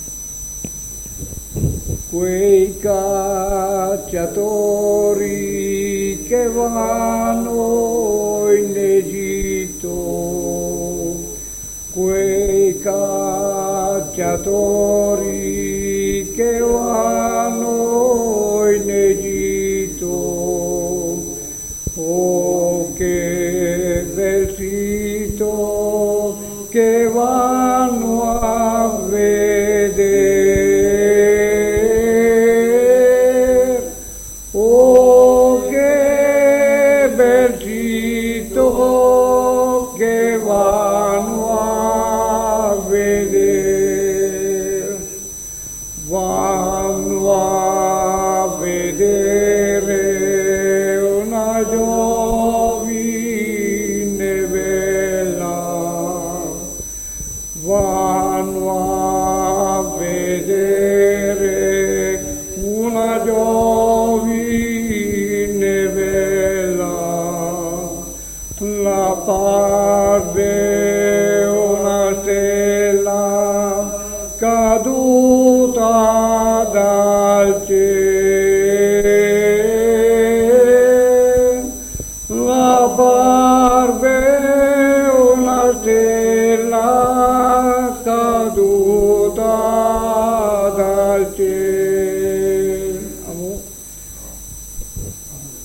Registrazioni di canti popolari effettuate presso la sede del Coro Genzianella di Condino. 11 settembre 1972. 1 bobina di nastro magnetico.